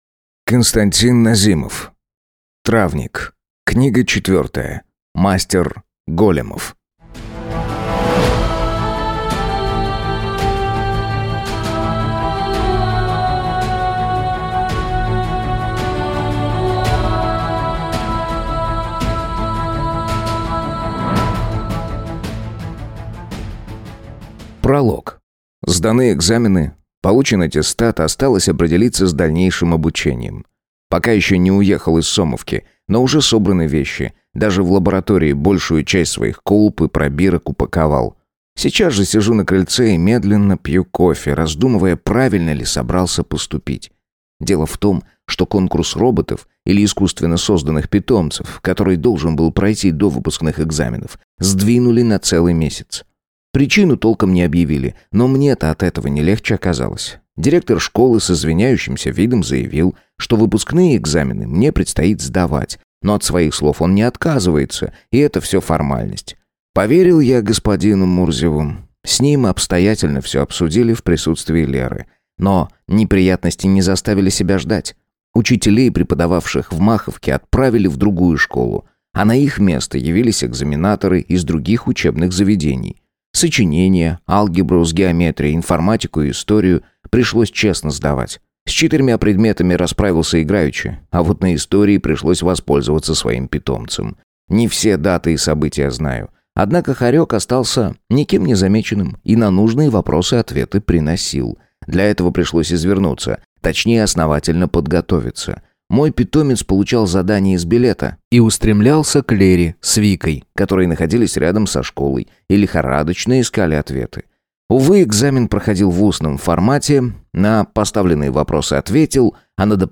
Аудиокнига Травник 4. Мастер Големов | Библиотека аудиокниг